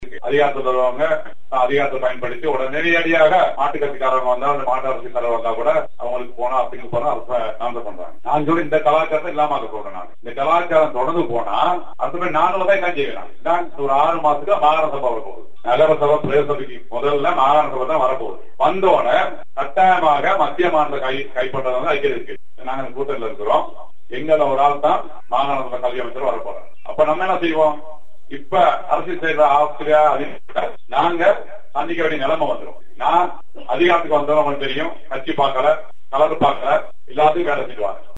ஹட்டன் – சாமிமலை பிரதேசத்தில் இடம்பெற்ற நிகழ்வில் கலந்து கொண்டு உரையாற்றிய போதே அவர் இதனை தெரிவித்தார்.